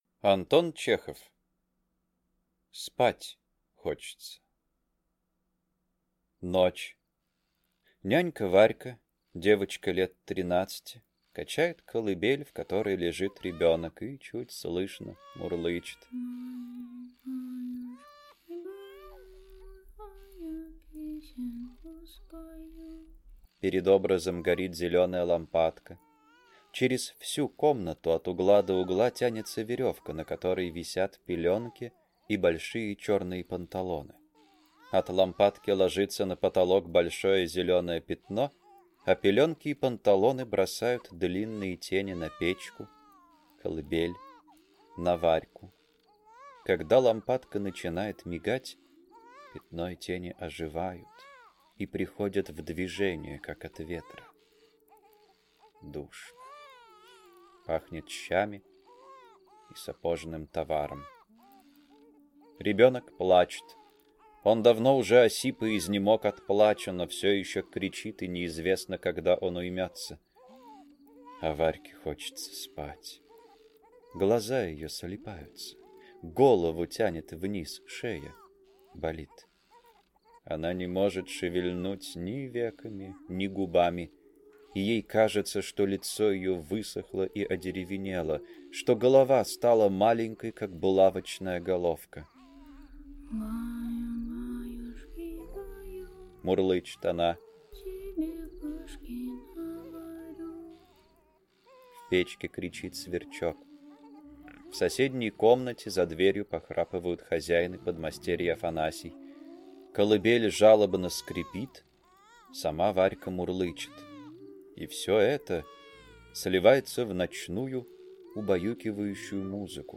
Аудиокнига Спать хочется | Библиотека аудиокниг
Читает аудиокнигу